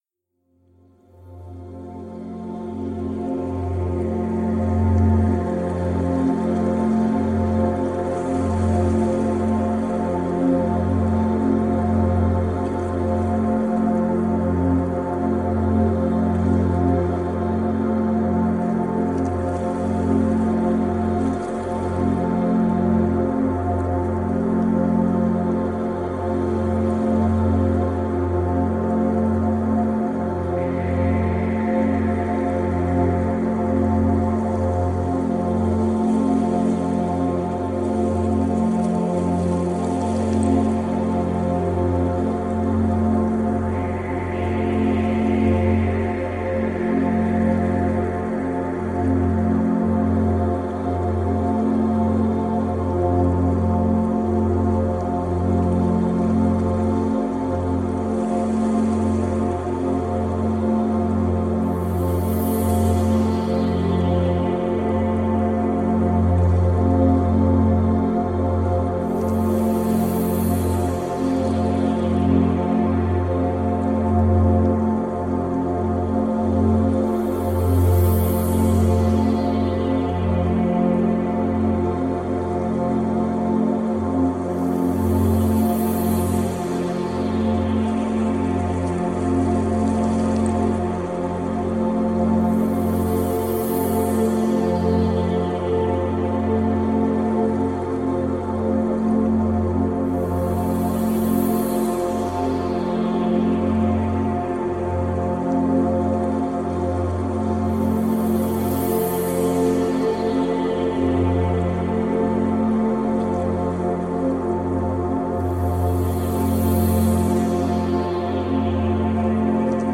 10hz - Alpha Binaural Beats for Positive Energy ~ Binaural Beats Meditation for Sleep Podcast